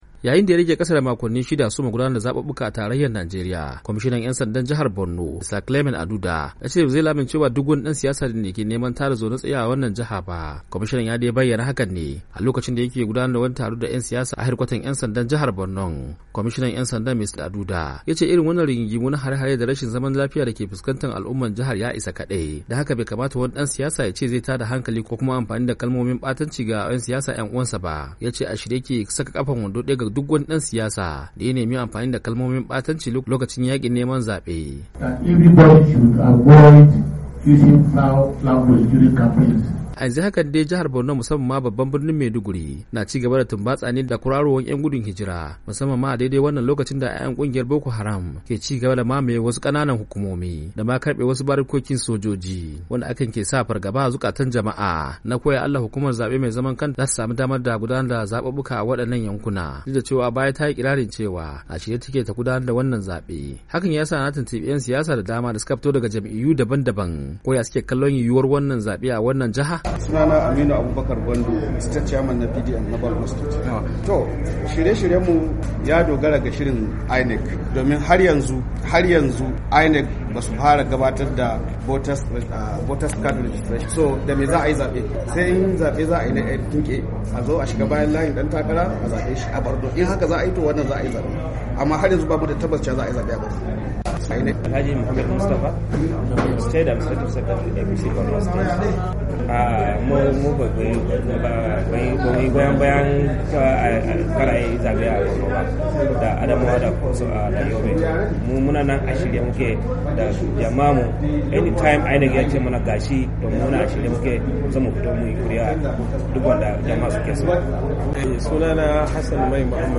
Ga rahoton rahoton